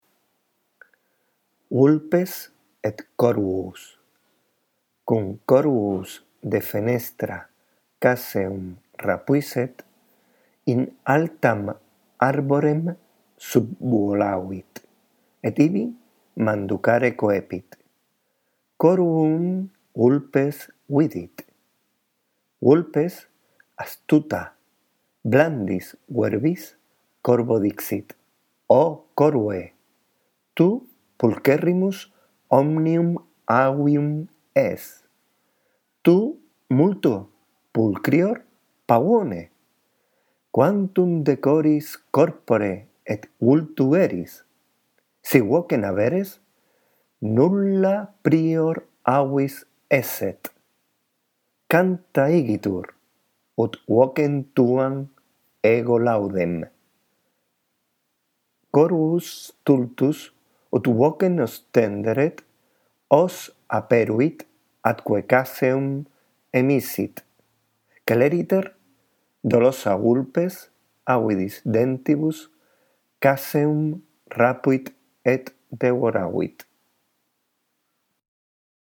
Tienes una lectura justo debajo del texto por si la necesitas.